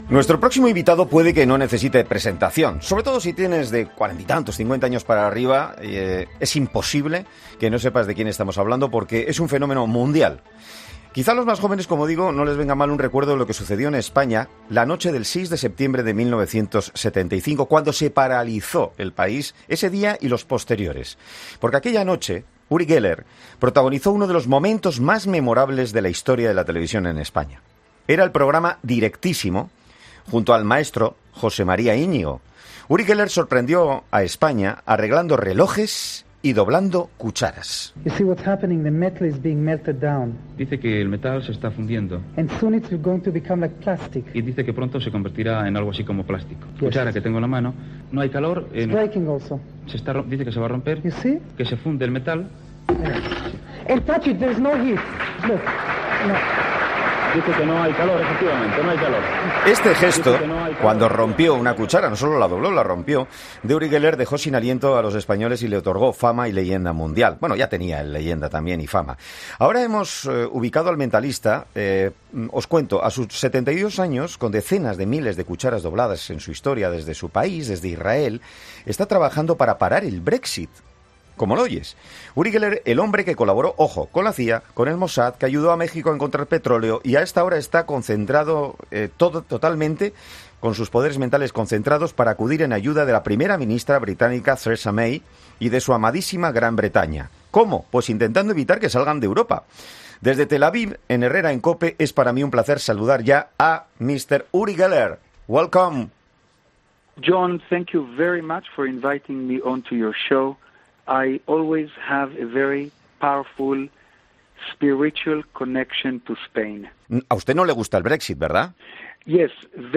A sus 72 años, este jueves ha sido entrevistado en 'Herrera en COPE' debido a la misión que se trae entre manos: paralizar el Brexit para evitar que los británicos salgan de la Unión Europea.